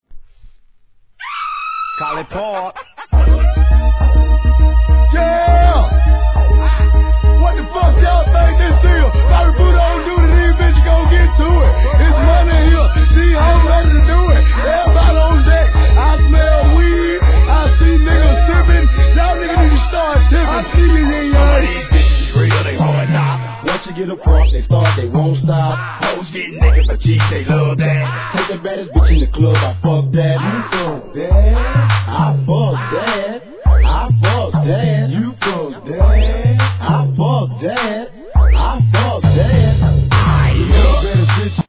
HIP HOP/R&B
頭からご機嫌な鳴り物でテンション上げる上がる!!